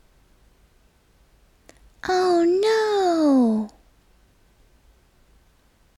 oh no.m4a
Category 🗣 Voices
feminine no oh-no speech talk talking voice word sound effect free sound royalty free Voices